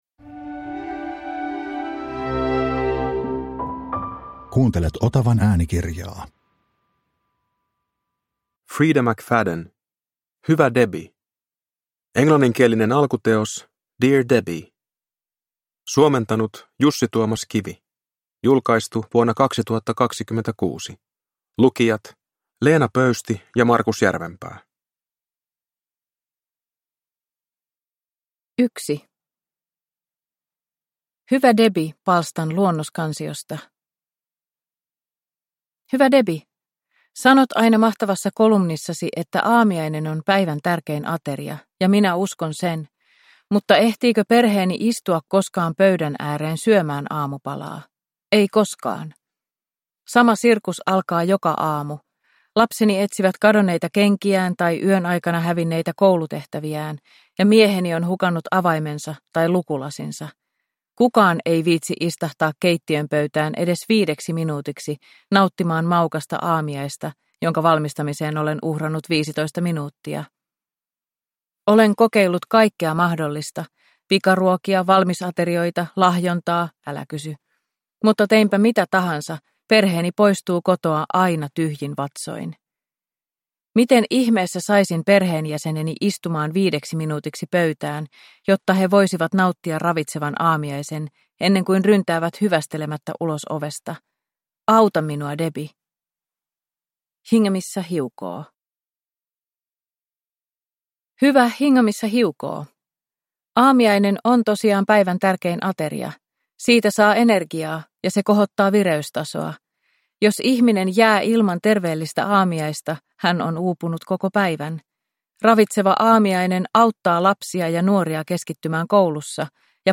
Hyvä Debbie – Ljudbok